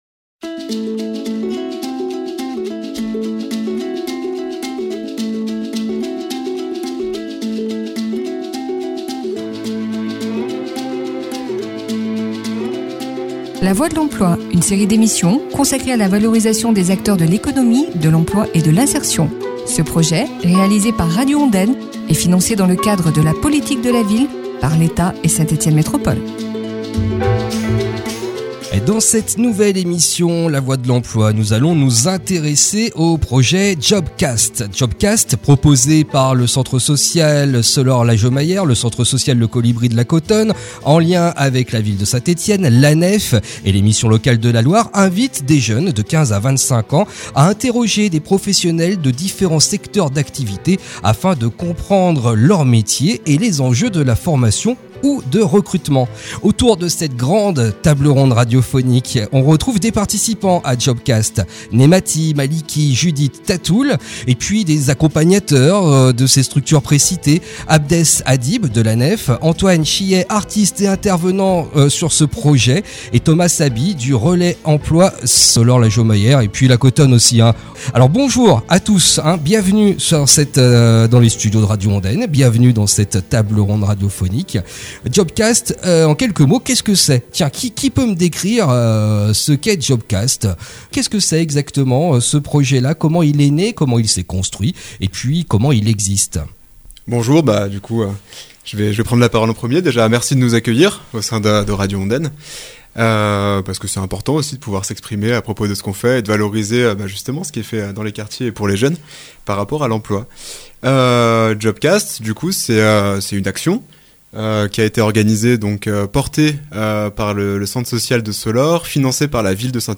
Table ronde radiophonique